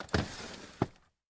Refridgerators | Sneak On The Lot